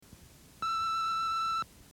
beep
It’s that beep that says there is a message on the answering machine.
beep.mp3